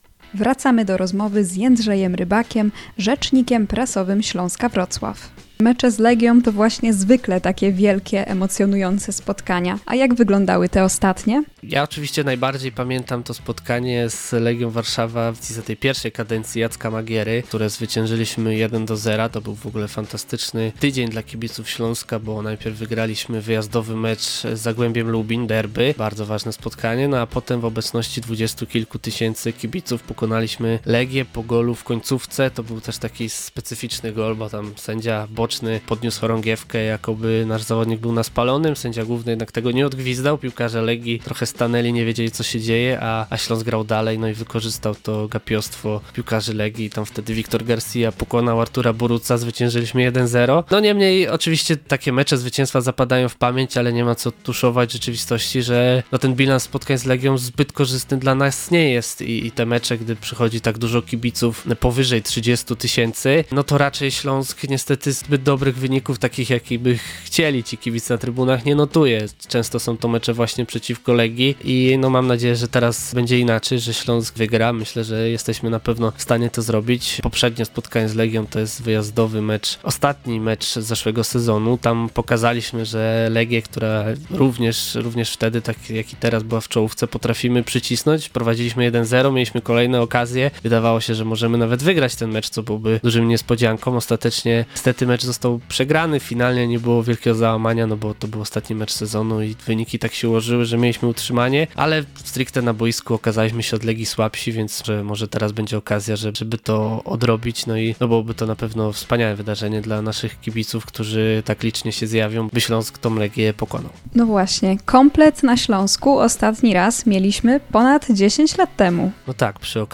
cz.2-rozmowy.mp3